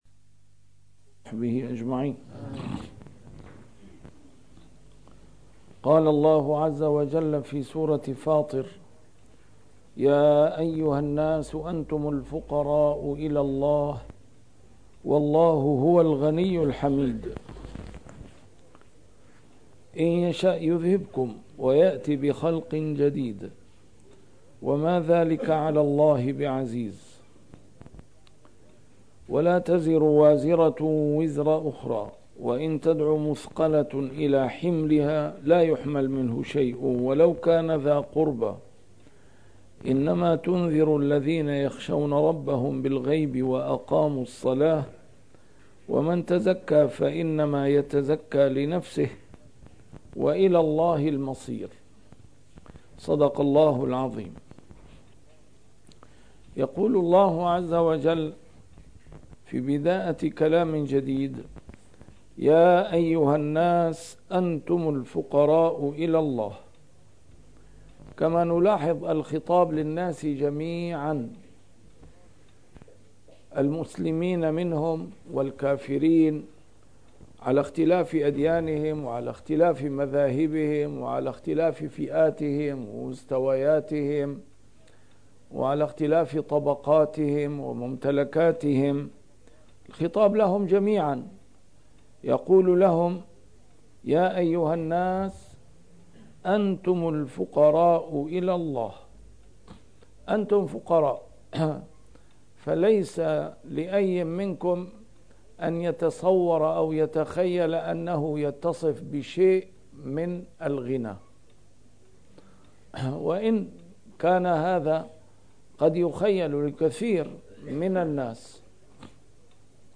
A MARTYR SCHOLAR: IMAM MUHAMMAD SAEED RAMADAN AL-BOUTI - الدروس العلمية - تفسير القرآن الكريم - تسجيل قديم - الدرس 413: فاطر 15-17
تفسير القرآن الكريم - تسجيل قديم - A MARTYR SCHOLAR: IMAM MUHAMMAD SAEED RAMADAN AL-BOUTI - الدروس العلمية - علوم القرآن الكريم - الدرس 413: فاطر 15-17